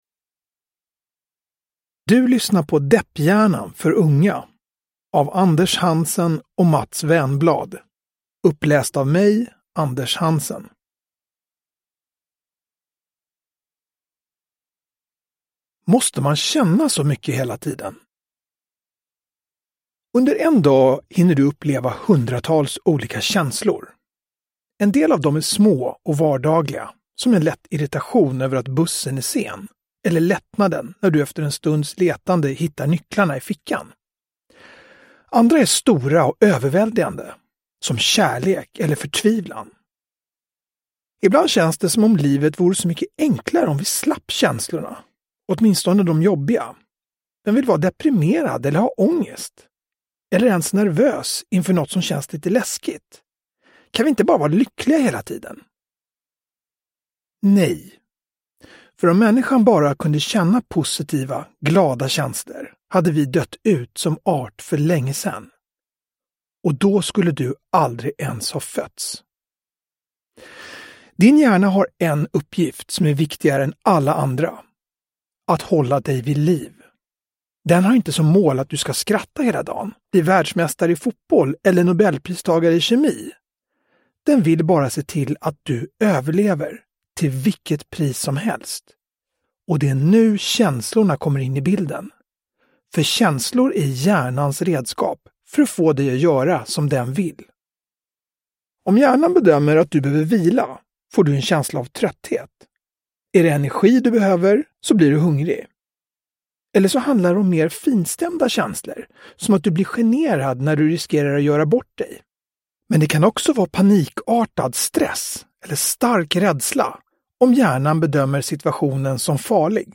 Depphjärnan för unga – Ljudbok – Laddas ner
Uppläsare: Anders Hansen